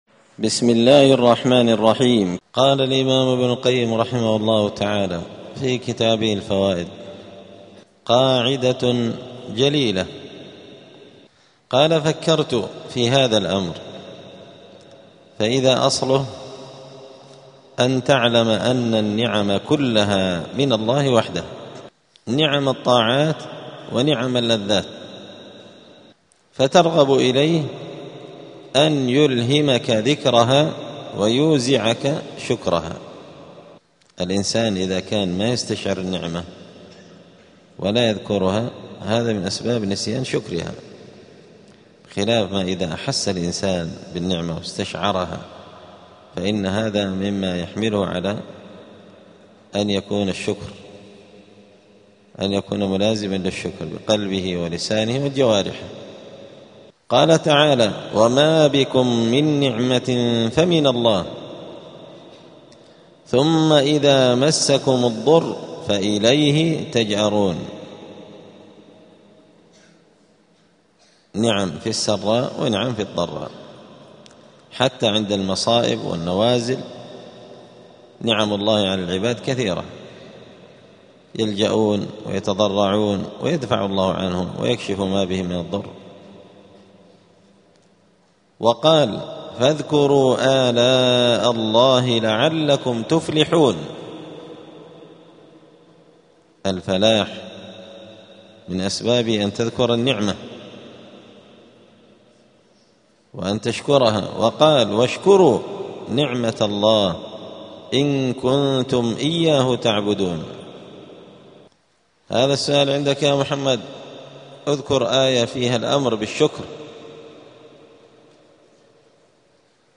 الجمعة 14 جمادى الآخرة 1447 هــــ | الدروس، دروس الآداب، كتاب الفوائد للإمام ابن القيم رحمه الله | شارك بتعليقك | 11 المشاهدات
دار الحديث السلفية بمسجد الفرقان قشن المهرة اليمن